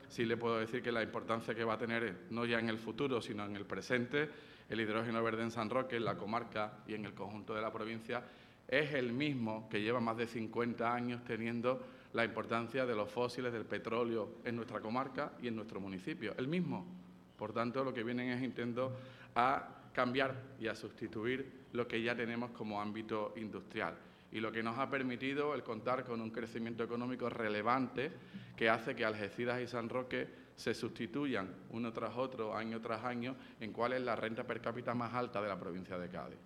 El presidente participa en las jornadas de la SER en San Roque